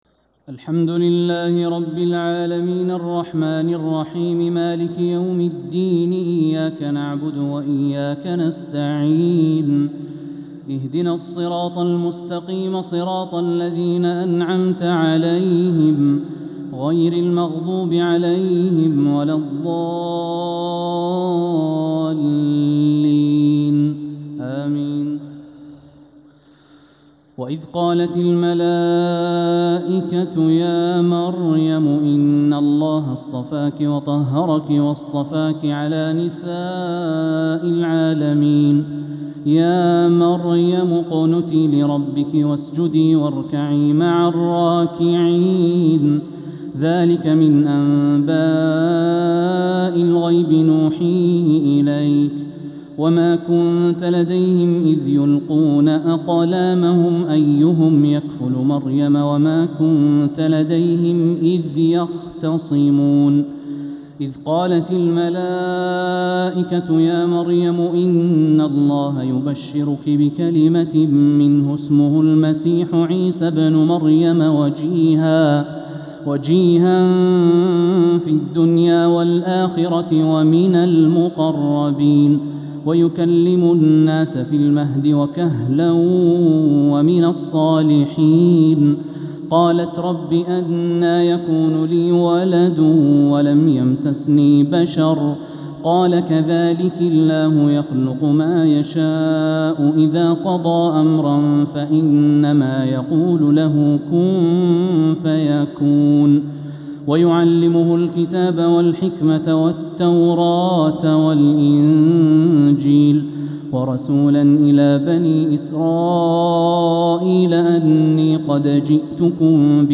تراويح ليلة 4 رمضان 1447هـ من سورة آل عمران {42-92} Taraweeh 4th night Ramadan 1447H Surah Aal-i-Imraan > تراويح الحرم المكي عام 1447 🕋 > التراويح - تلاوات الحرمين